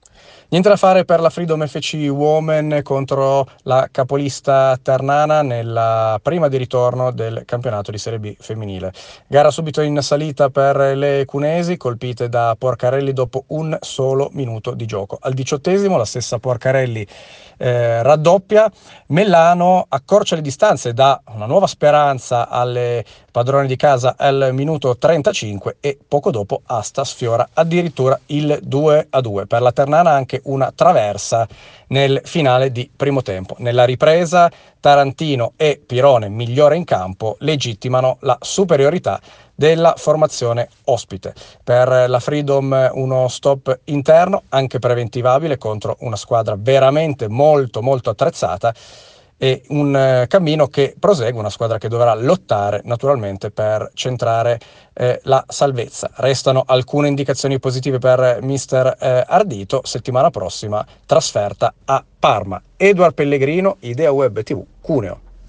Nuovo appuntamento con la rubrica di IDEAWEBTV “95° Minuto”: a pochi minuti dal termine delle partite di calcio, i commenti a caldo dei nostri inviati sui campi della Granda.